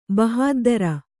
♪ bahāddara